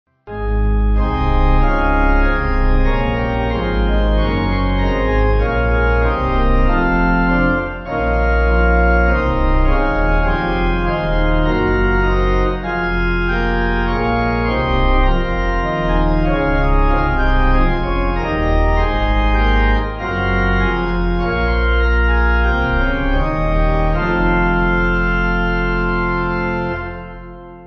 (CM)   3/Fm